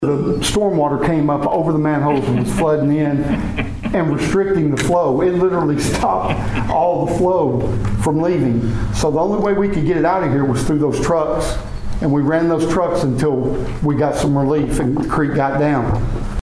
During Tuesday evening's city council meeting, Pawhuska City Manager Jerry Eubanks highlighted a problem with the old infrastructure in the town's sewer system.